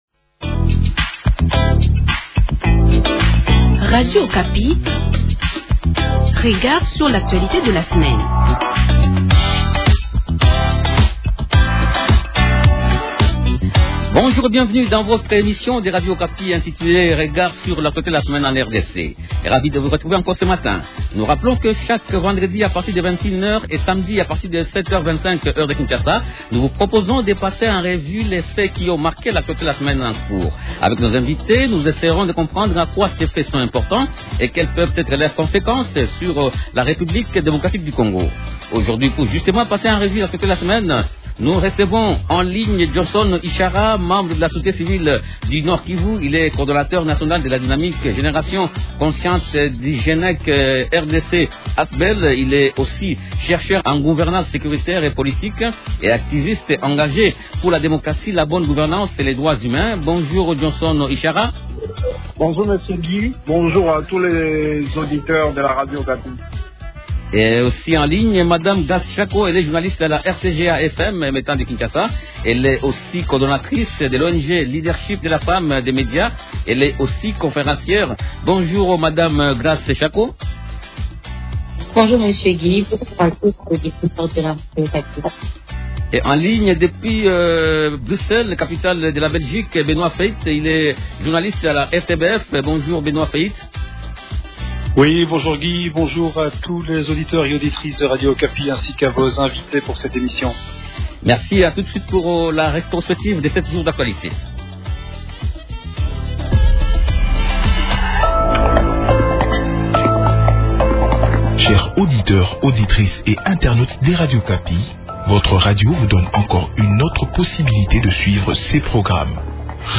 Trois sujets principaux au menu de cette émission :